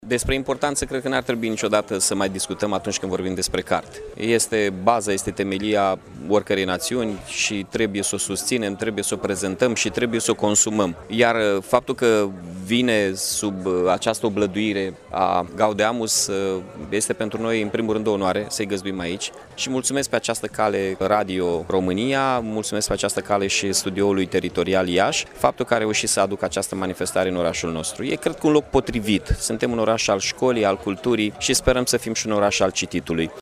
Peste 40 de edituri din țară sunt prezente, începând de astăzi, la Iași, la cea de-a treia ediție a Târgului de Carte „Gaudeamus – Radio România”.
Primarul municipiului, Mihai Chirica, a declarat că Iașul rămâne un punct de referință a culturii românești și a accentuat importanța unui asemenea eveniment în capitala Moldovei: